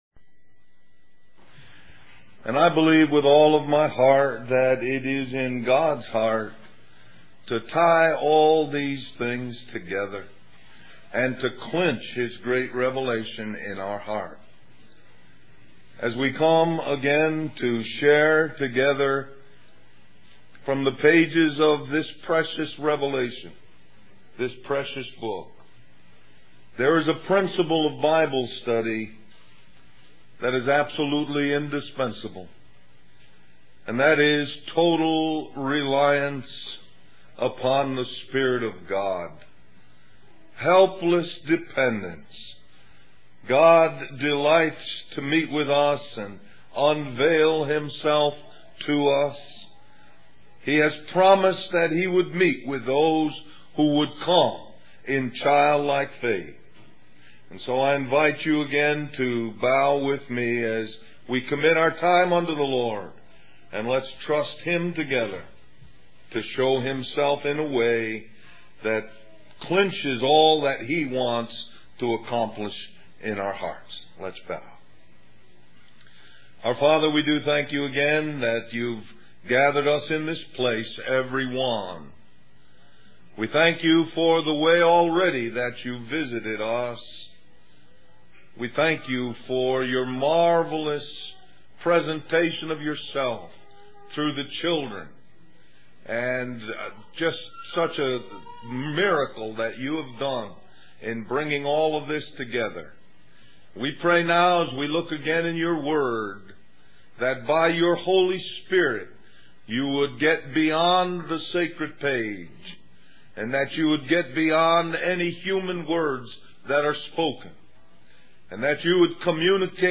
Del-Mar-Va Labor Day Retreat